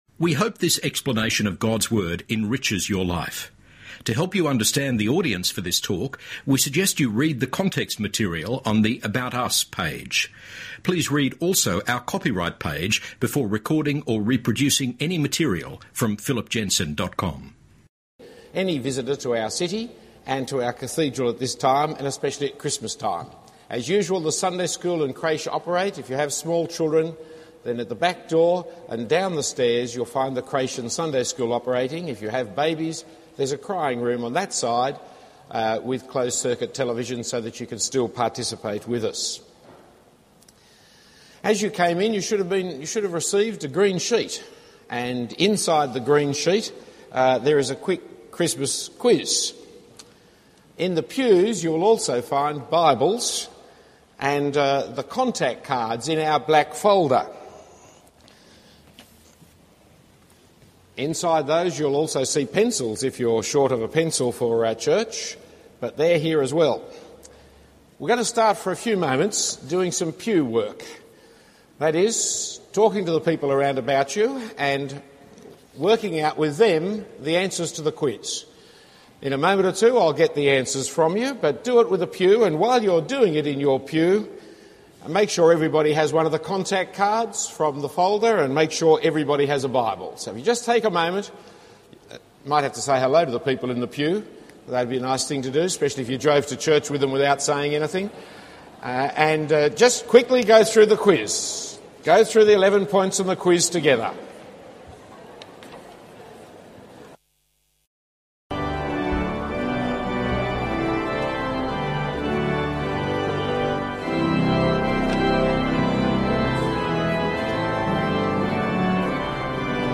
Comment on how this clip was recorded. Full Christmas Service at St Andrews Cathedral